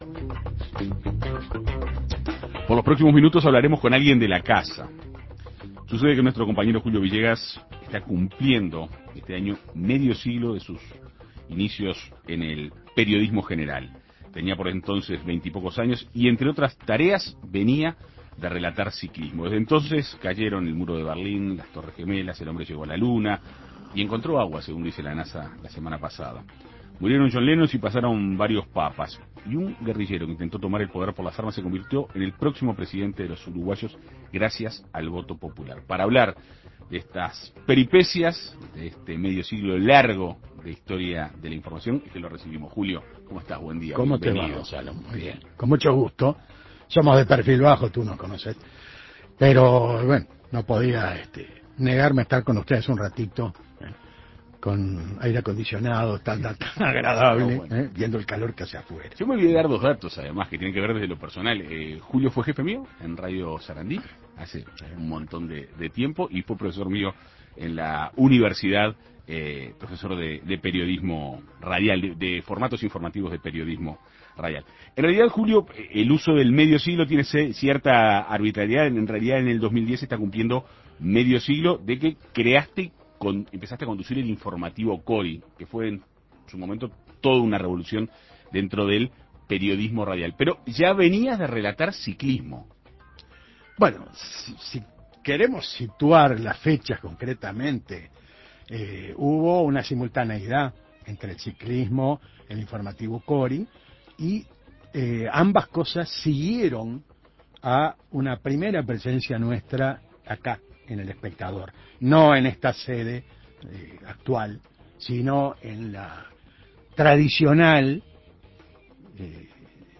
En Perspectiva Segunda Mañana dialogó con él para conocer detalles de su vida en el periodismo.